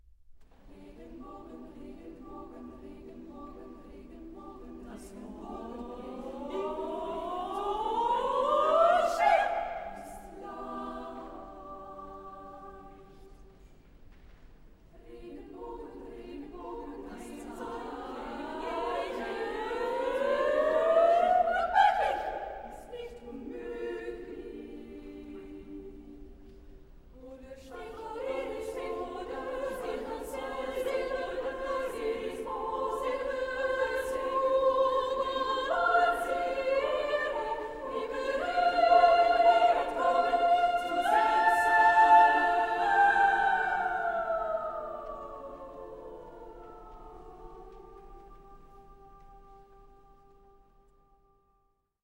Secular. Poem. Contemporary.
Consultable under : 20ème Profane Acappella